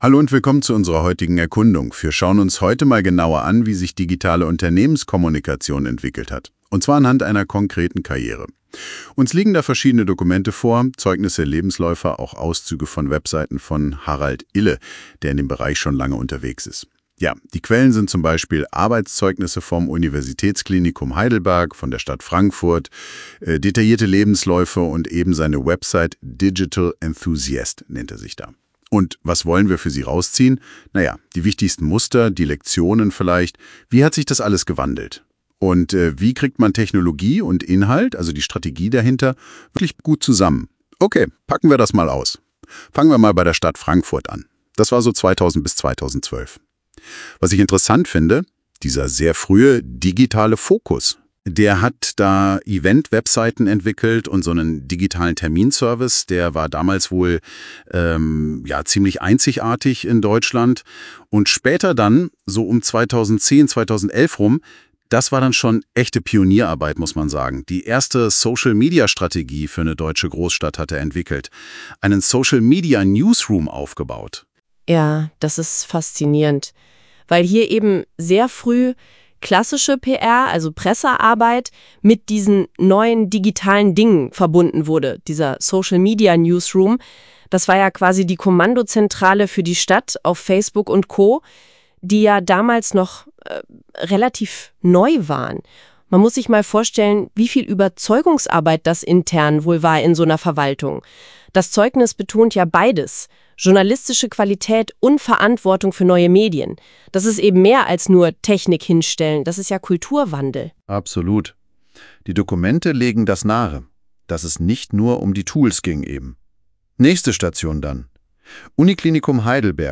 Die KI von NotebookLM über mich